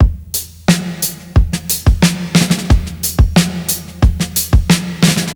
• 90 Bpm Breakbeat Sample D# Key.wav
Free drum groove - kick tuned to the D# note. Loudest frequency: 2392Hz
90-bpm-breakbeat-sample-d-sharp-key-RV4.wav